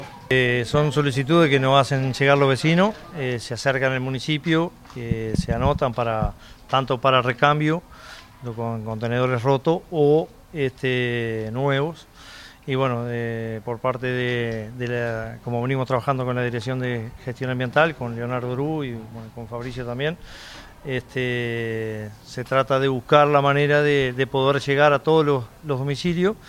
alcalde_leonardo_perez_0.mp3